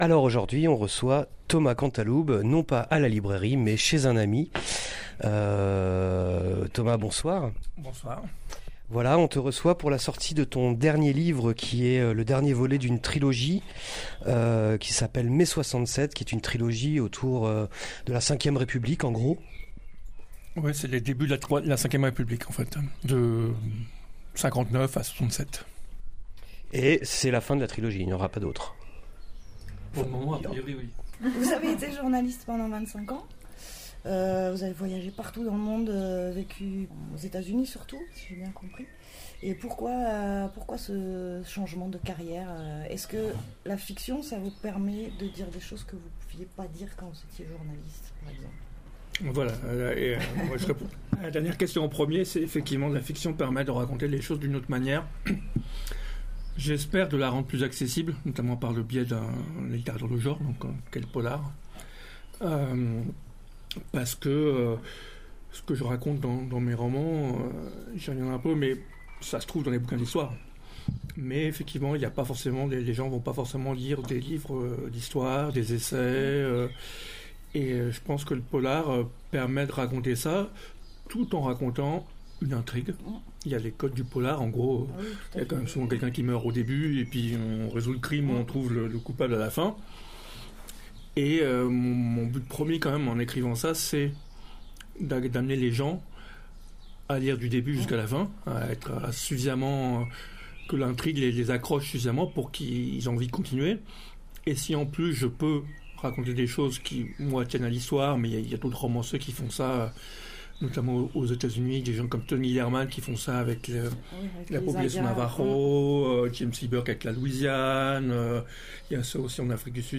Interview pour son livre Mai 67